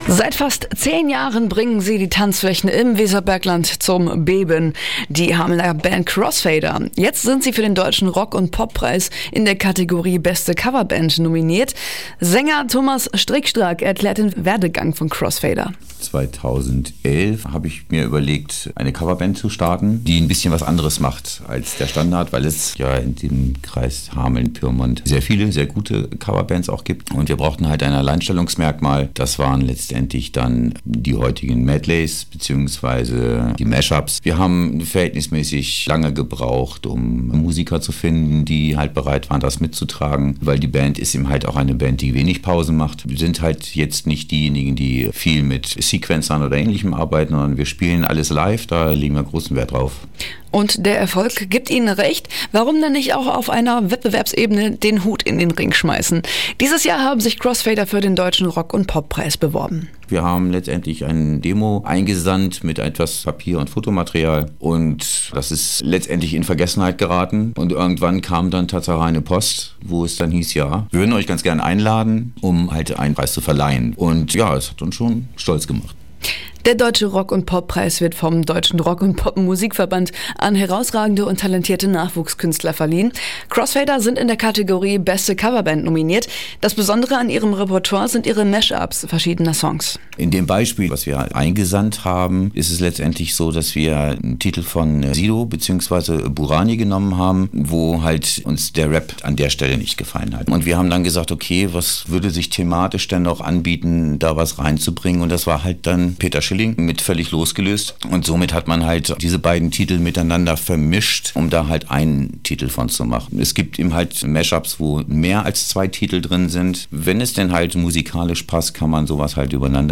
RadioAktiv Interview